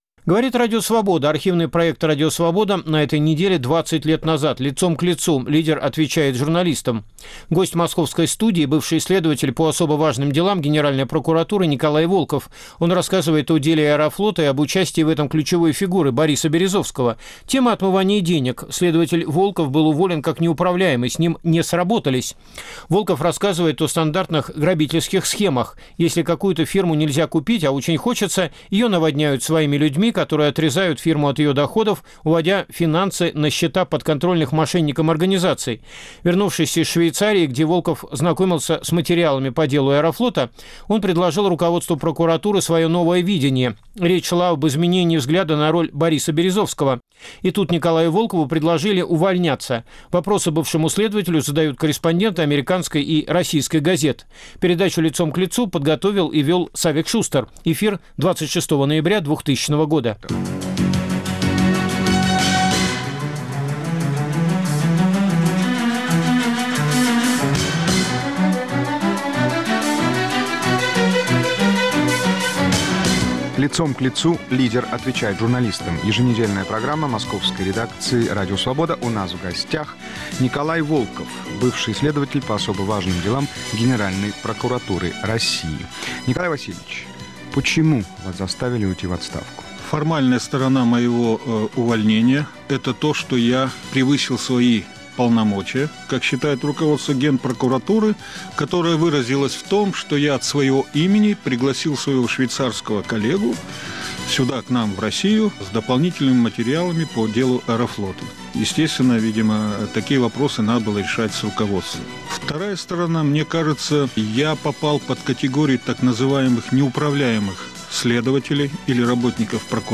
Лицом к лицу: лидер отвечает журналистам.